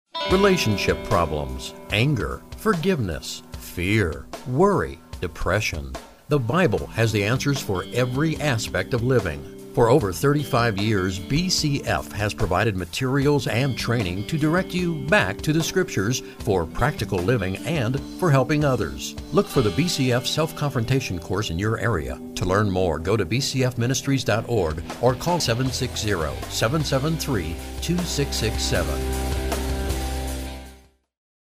Audio Segments Commercials
30-Second Radio Spot:
bcf_commercial_general.mp3